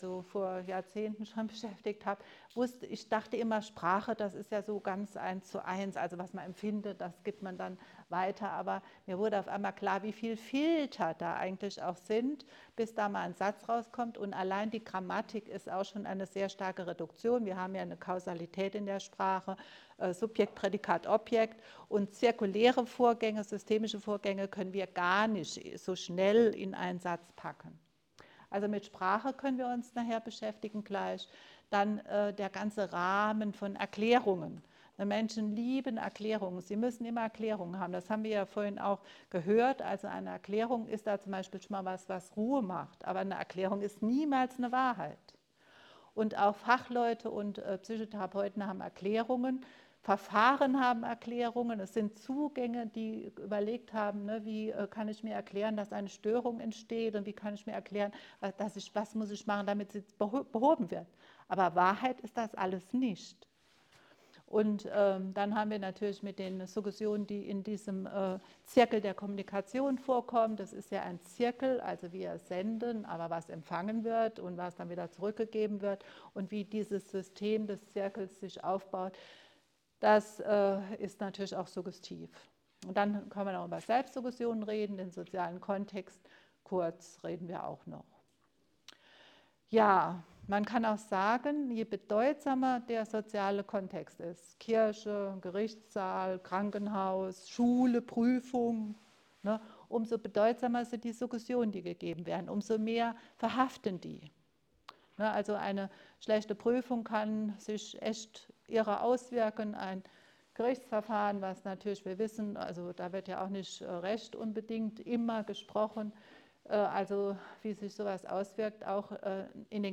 Wie betreffen sie die Psychotherapie?Vortrag 25.05.2024, Siegburg: 29. Rheinische Allgemeine PSYCHOtherapietagung: Die Verantwortung der Suggestion - inszenierte Zuversicht